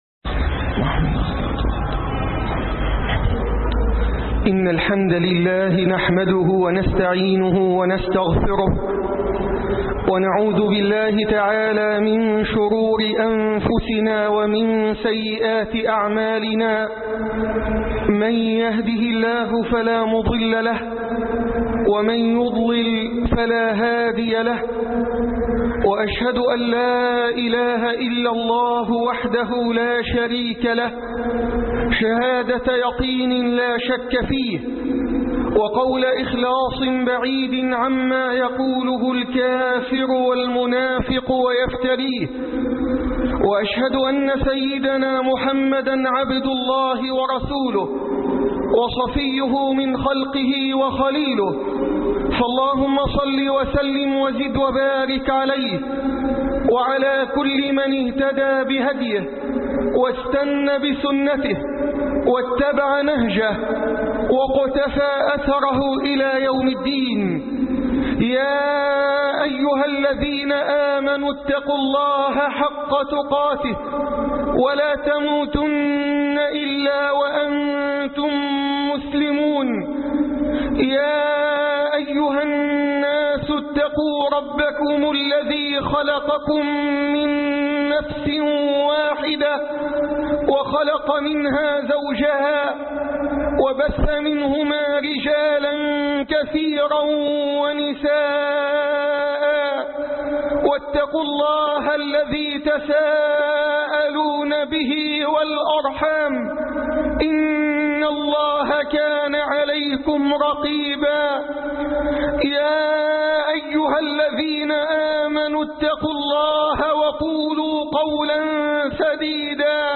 ضياع الأمانة (خطب الجمعة